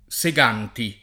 [ S e g# nti ]